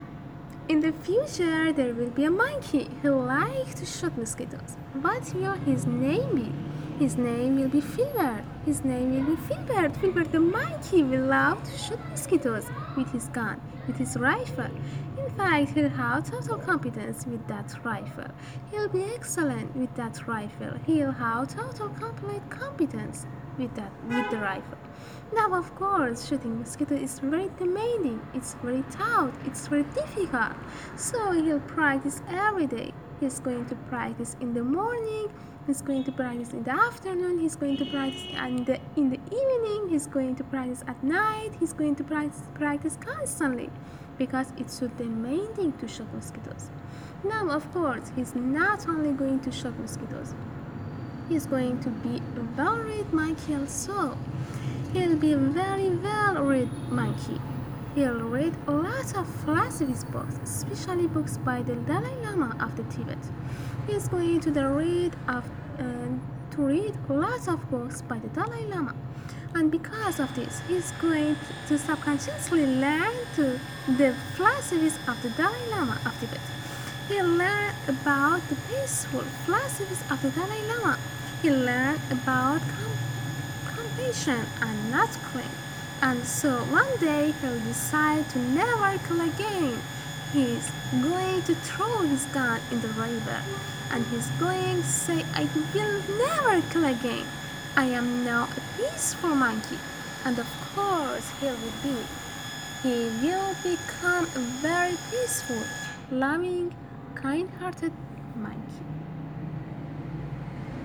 معذرت واسه صدا پمپ :sweat_smile::sweat_smile:
صدای پمپ و صدای بوغ ماشین, :grin::grinning_face_with_smiling_eyes::stuck_out_tongue_winking_eye: